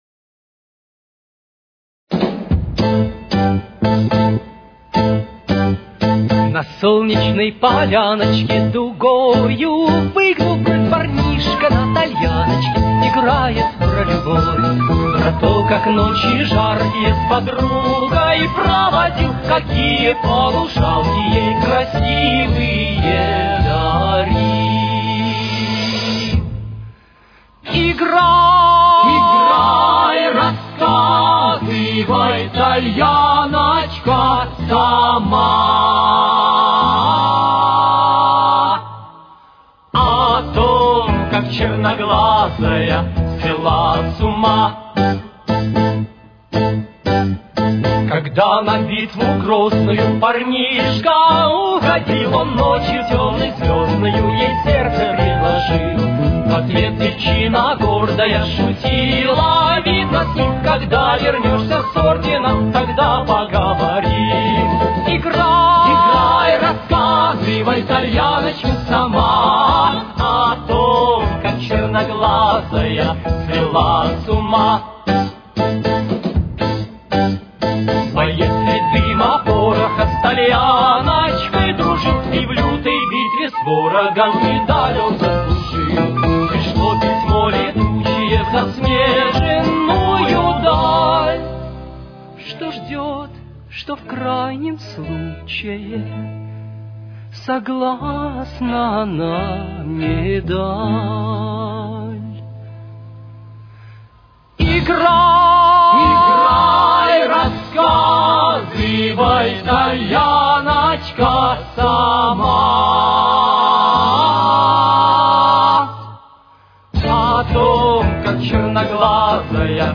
Ля минор. Темп: 116.